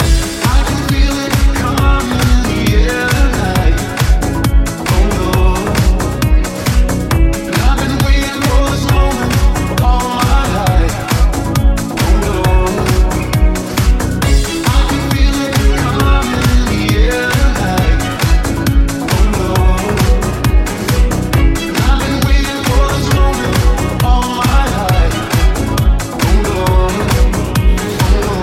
Genere: deep, house, club, remix